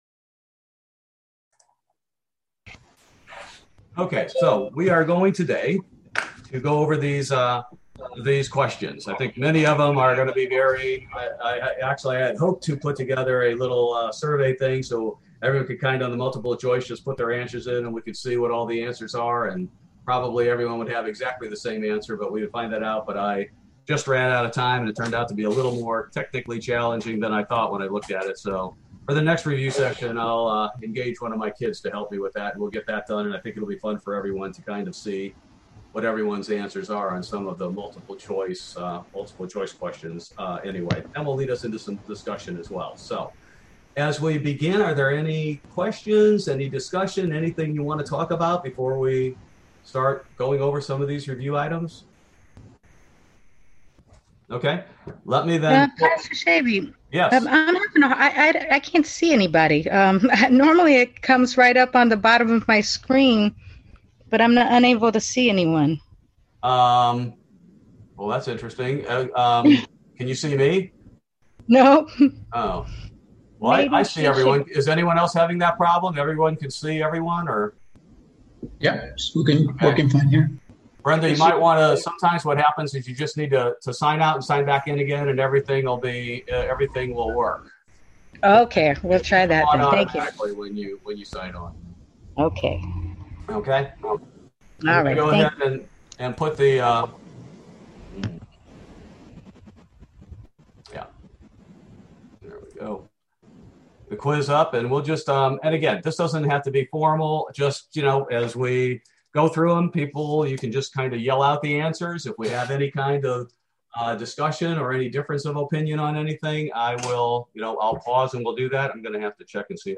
Bible Study: July 7, 2021